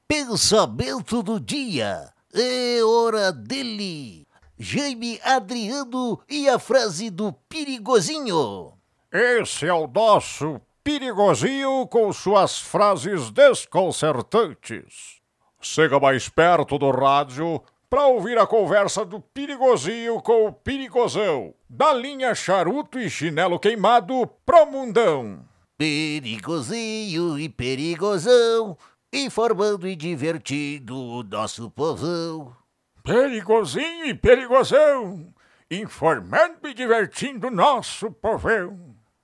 Caricata: